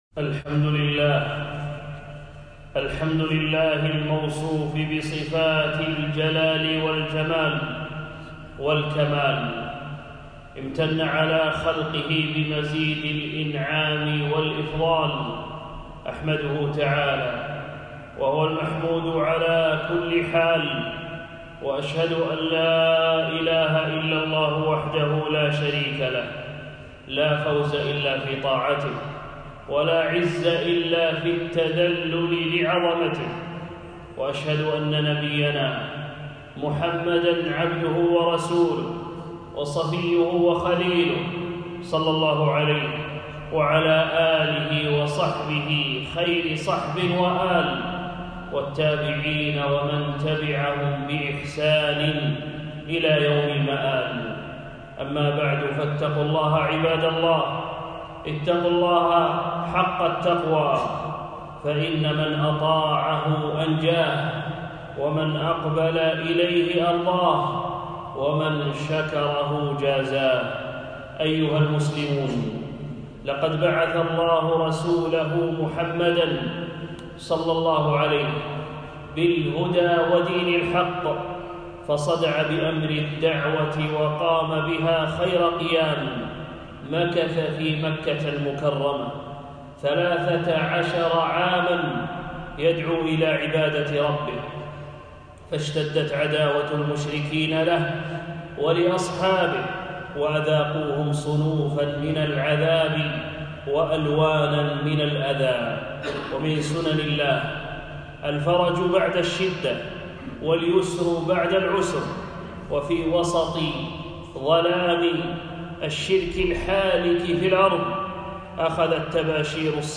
خطبة - فضائل المدينة النبوية